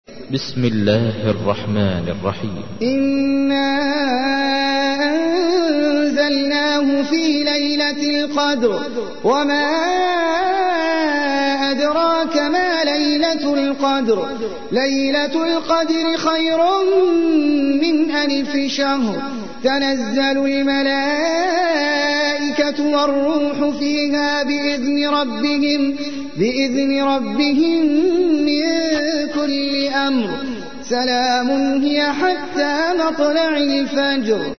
تحميل : 97. سورة القدر / القارئ احمد العجمي / القرآن الكريم / موقع يا حسين